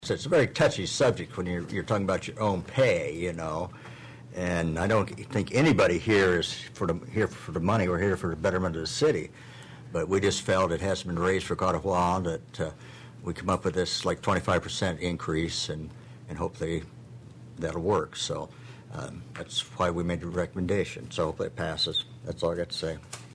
City Councilman Pat McCurdy says the increase was discussed at length during that July committee meeting.